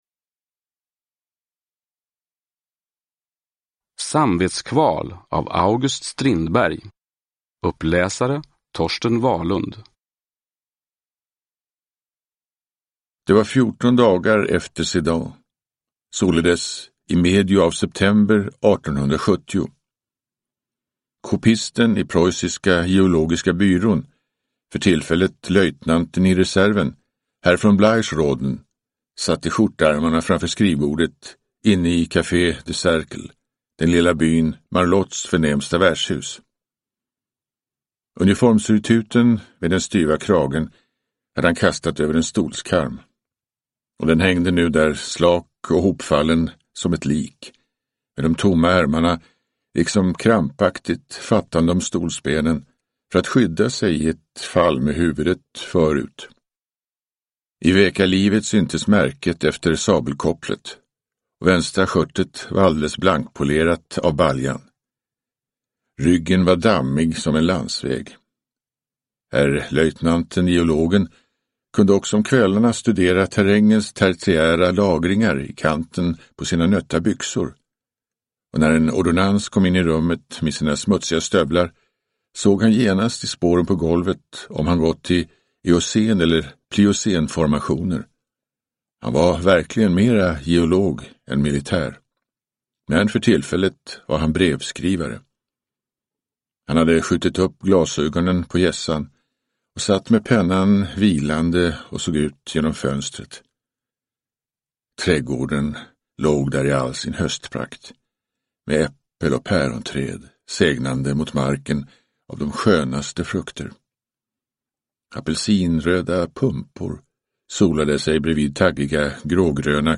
Samvetskval – Ljudbok
Uppläsare: Torsten Wahlund